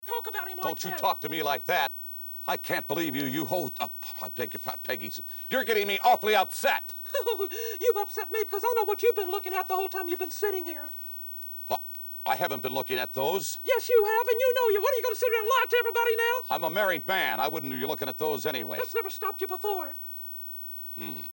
However, Gene goes FULL PERV and stares at her cleavage while making drooling sounds.